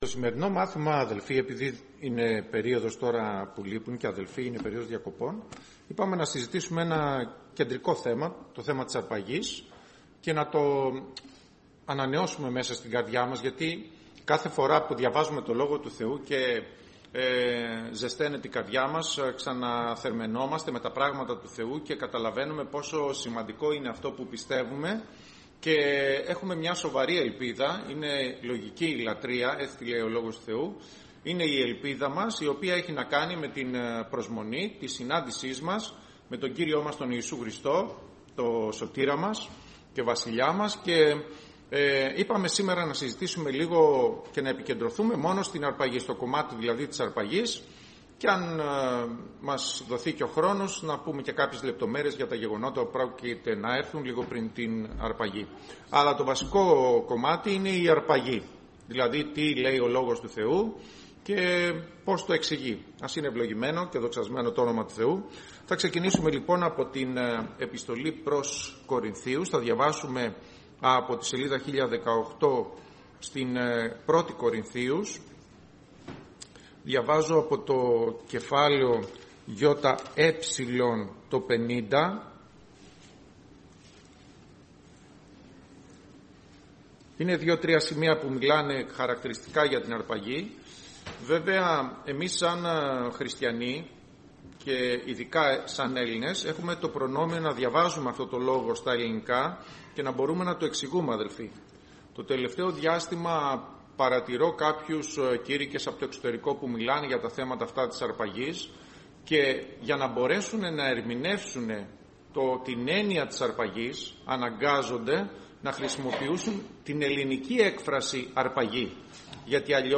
Όλα τα Κηρύγματα